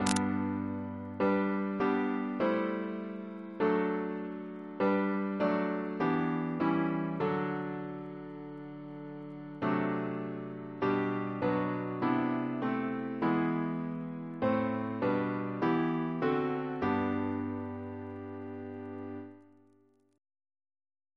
Double chant in G Composer: Sir Ivor Algernon Atkins (1869-1953), Organist of Worcestor Cathedral Reference psalters: ACP: 37